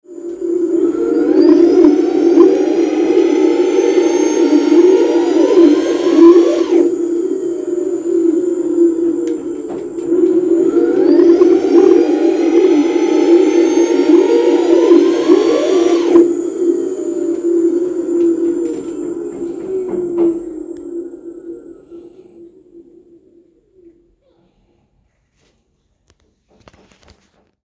Vibhrationsgeräusche Motor
Einer meiner Ninebot G30D II macht auch immer sehr komische Geräusche.